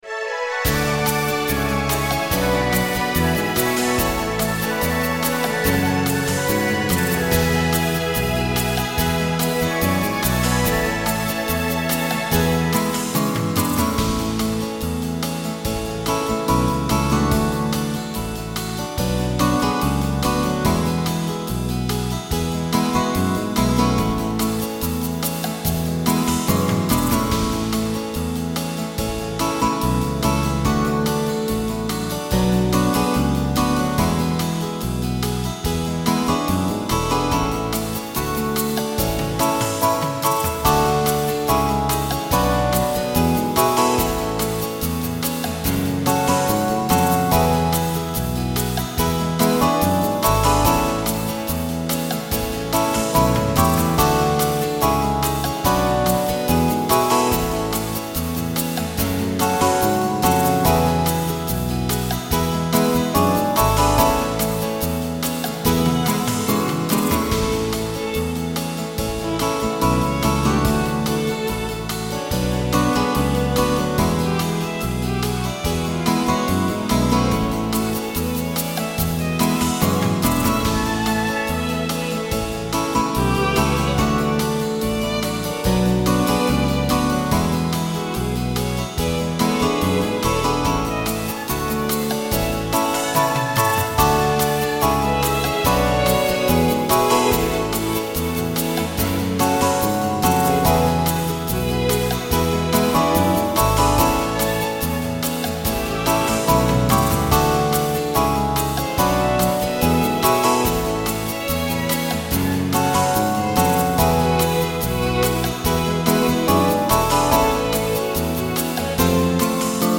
country style